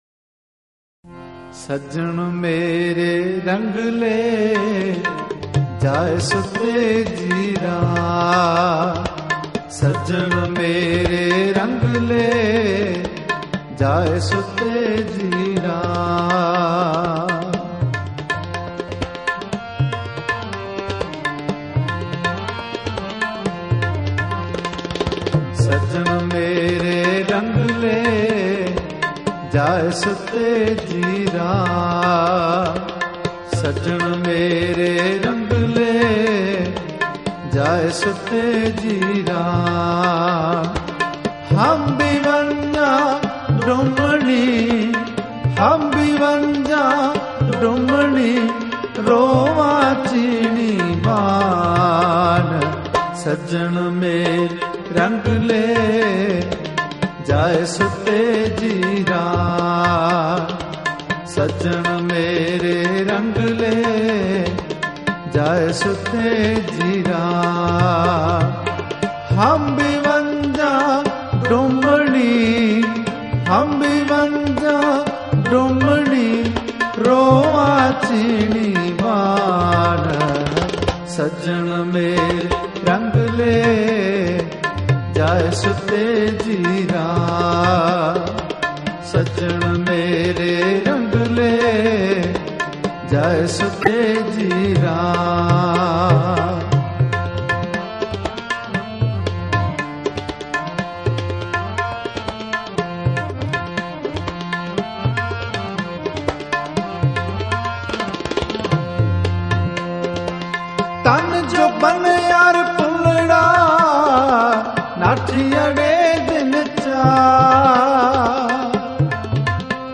Gurbani Kirtan